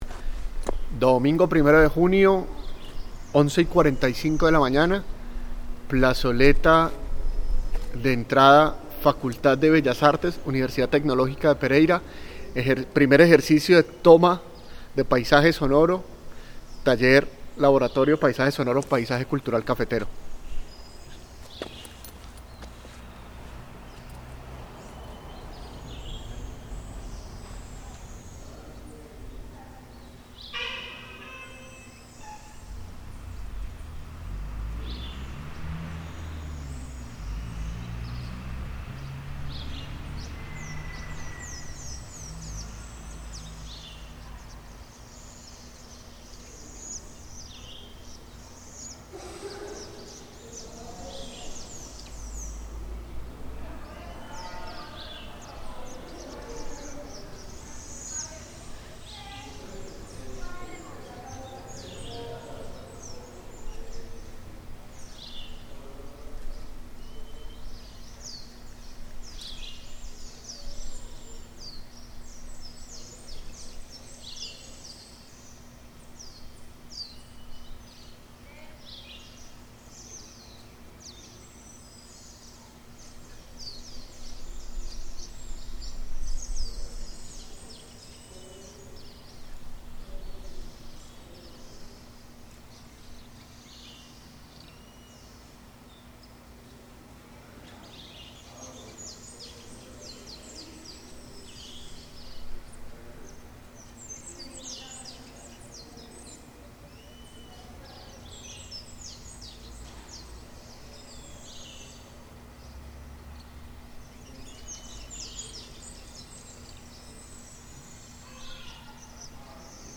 Paisaje sonoro de la Plaza que da a la entrada de la Facultad de Bellas Artes de la Universidad Tecnológica de Pereira. Hace parte del Proyecto Paisaje sonoro Paisaje Cultural Cafetero.